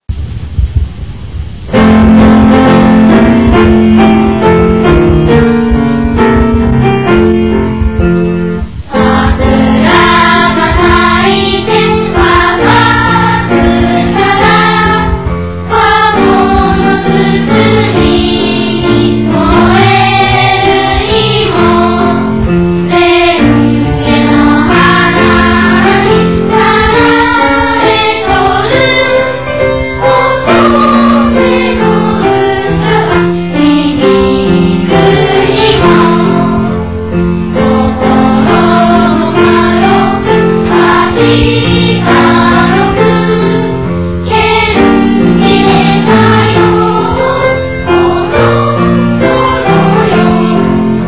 また、学園の楽しさ、学ぶことの大切さ、希望に向かっての志などが歌いこまれている。 曲もまた大久保のおだやかな風土心情をもりこんだ子どもらしい旋律です。